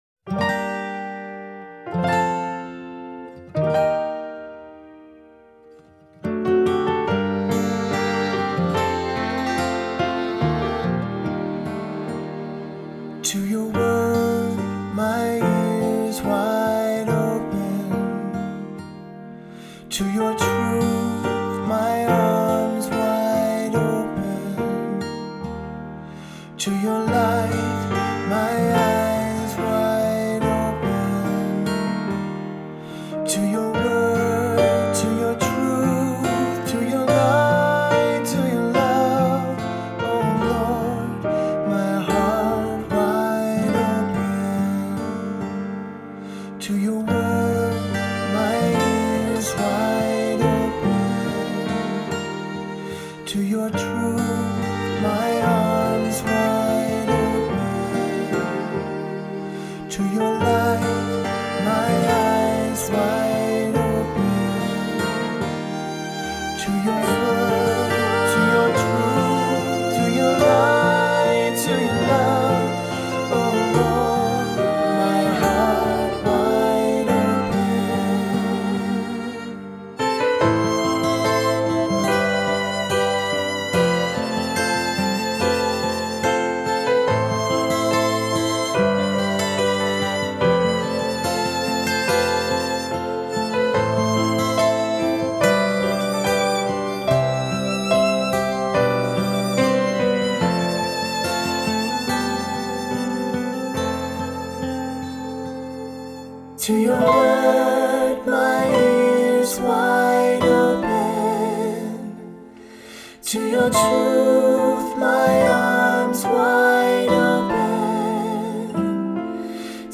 Voicing: SAB; Cantor; Assembly